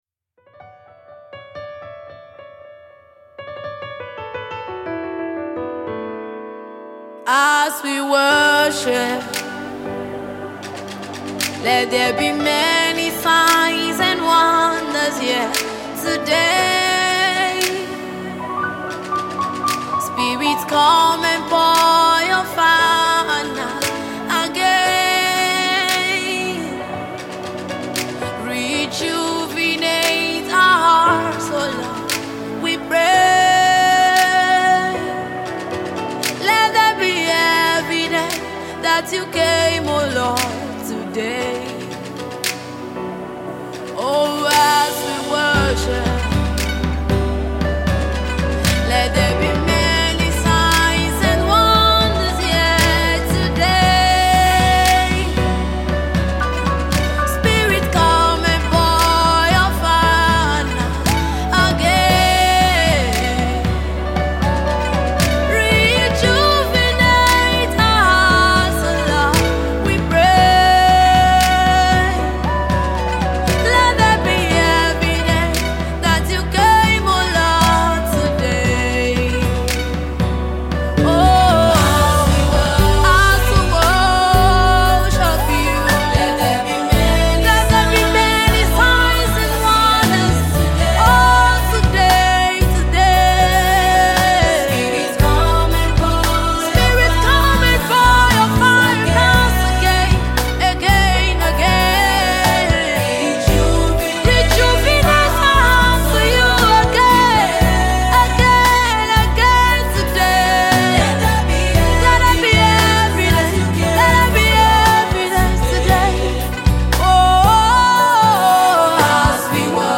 soul-lifting worship anthem